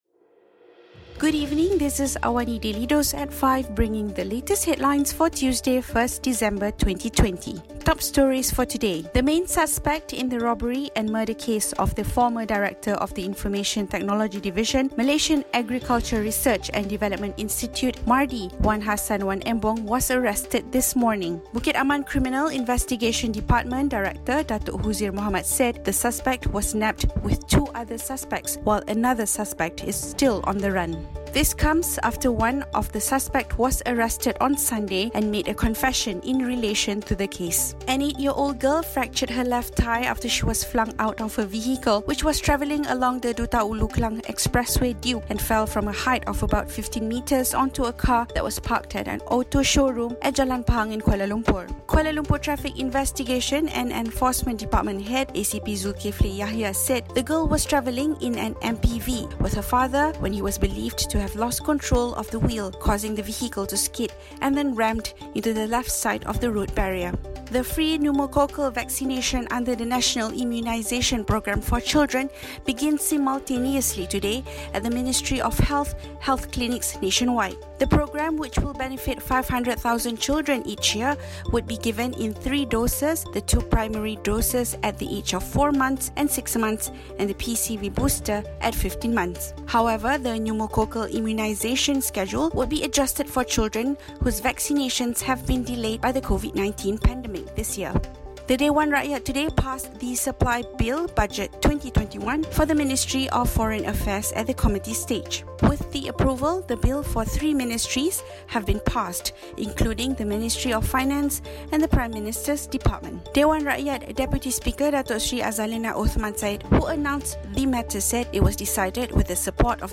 Also, China has provided North Korean leader Kim Jon-un and his family with an experimental COVID-19 vaccine. Listen to the top stories of the day, reporting from Astro AWANI newsroom — all in 3 minutes.